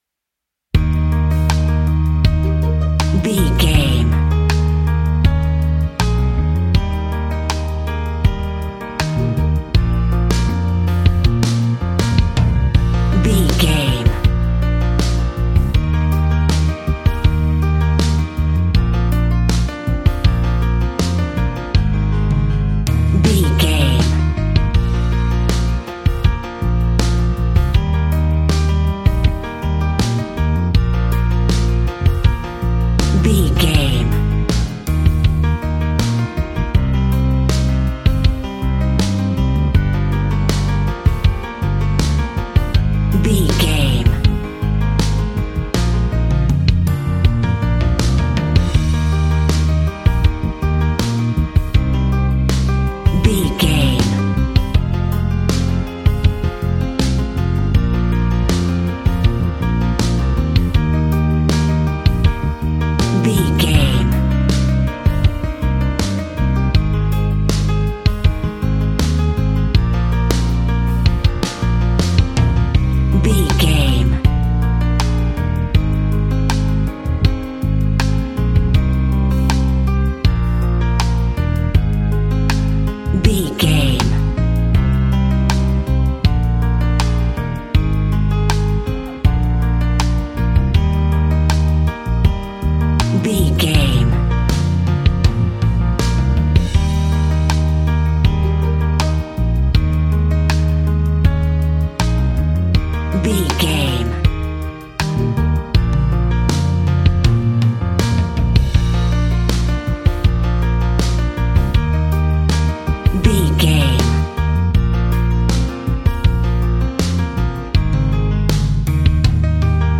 Ionian/Major
E♭
romantic
sweet
acoustic guitar
bass guitar
drums